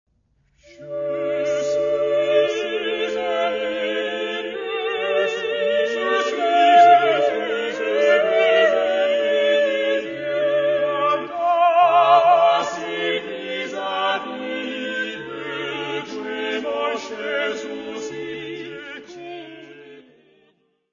Mary's music : Songs and dances from the time of Mary Queen of Scots
Área:  Música Clássica